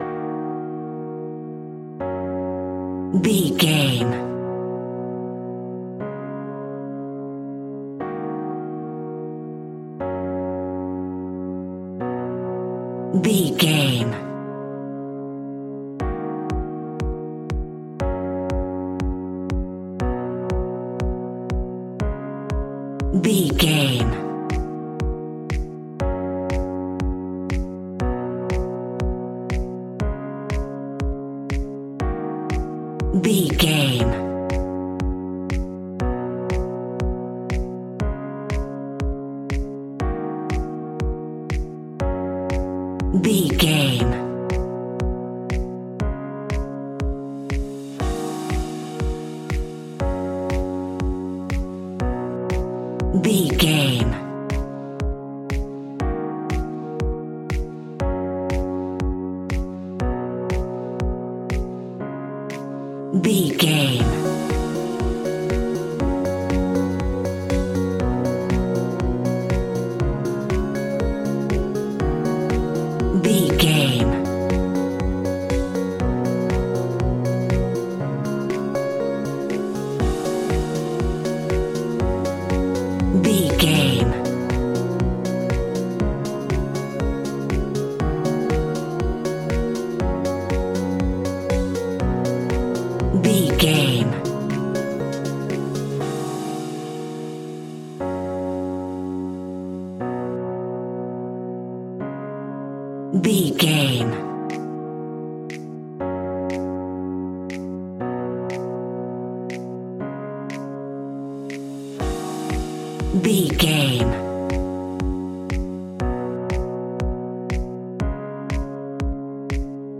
Aeolian/Minor
E♭
groovy
uplifting
hypnotic
dreamy
tranquil
piano
drum machine
synthesiser
electro house
funky house
synth leads
synth bass